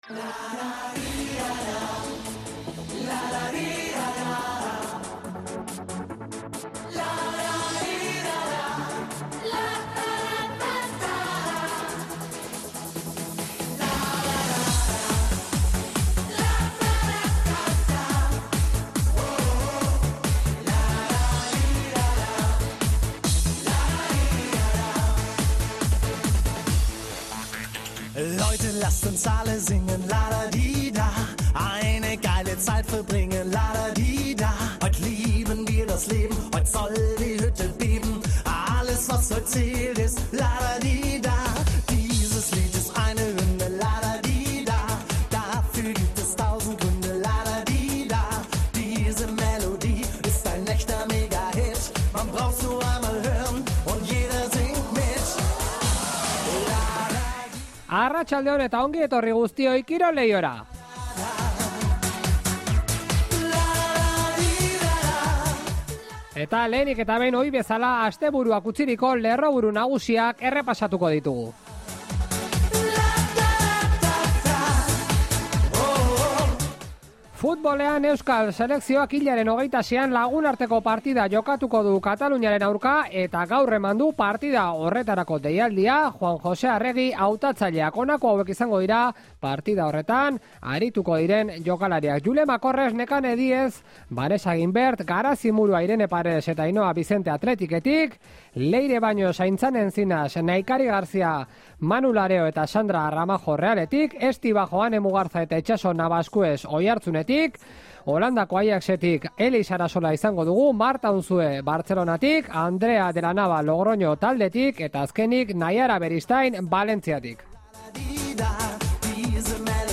Gaurko Kirol Leihoan lehenbizikoz (salbuespen moduan) gizonezko kirolari bat elkarrizketatu dugu.